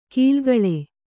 ♪ kīlgaḷe